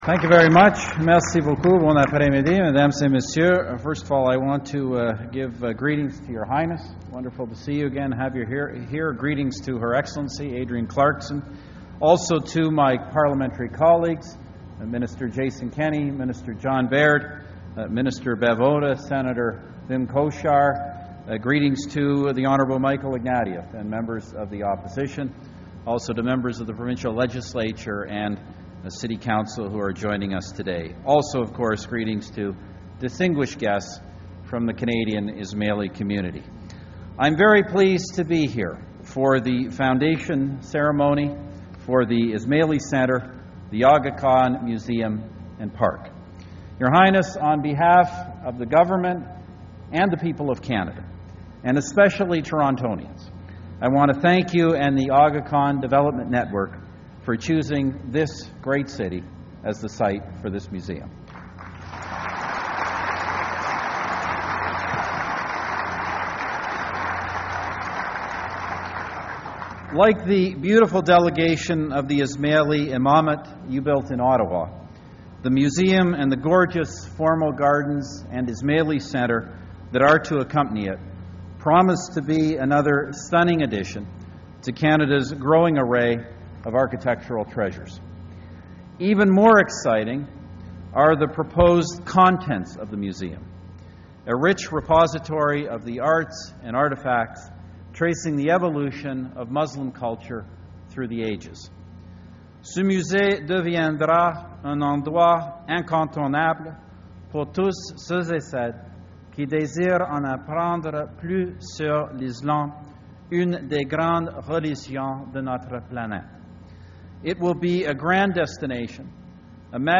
Audio: PM Stephen Harper welcomes Aga Khan to Canada